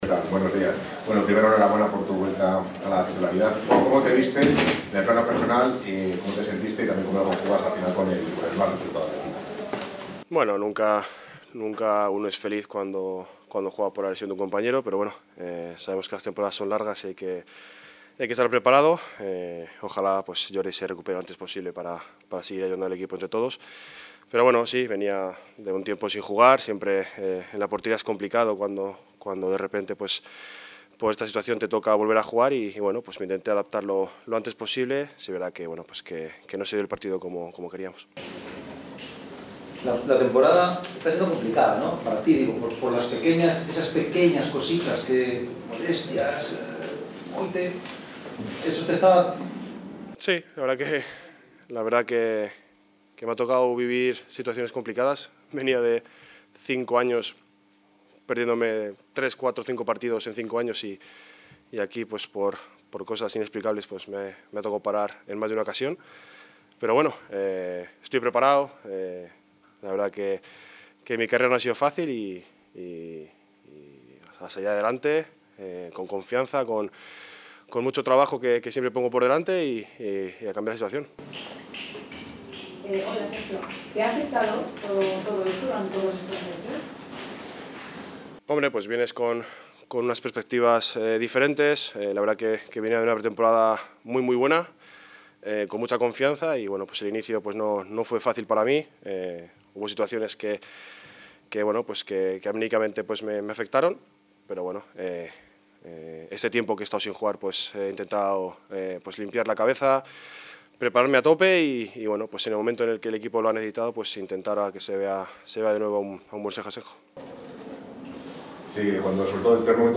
“Nunca uno es feliz cuando juega por la lesión de un compañero. La temporada es larga para prepararse. Ojalá Jordi se recupere pronto. Me intenté adaptar lo antes posible, aunque no se dio el partido como queríamos”, expresó este jueves en rueda de prensa.